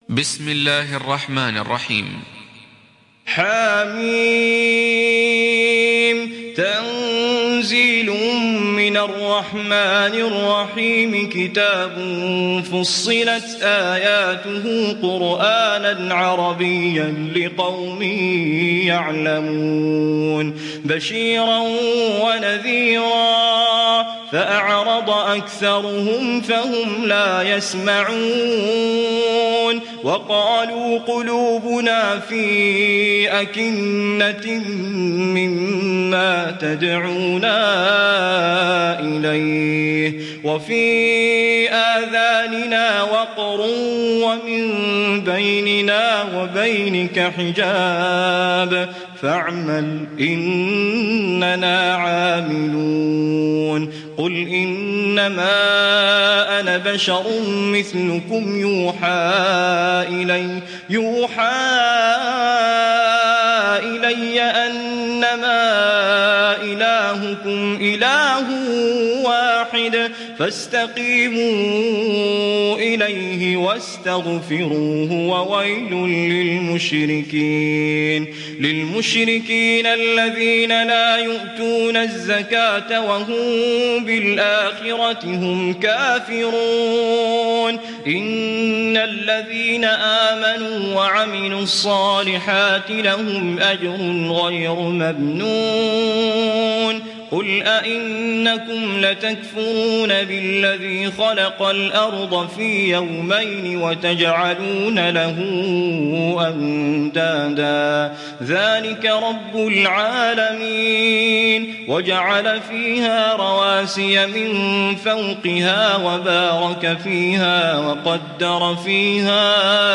تحميل سورة فصلت mp3 بصوت توفيق الصايغ برواية حفص عن عاصم, تحميل استماع القرآن الكريم على الجوال mp3 كاملا بروابط مباشرة وسريعة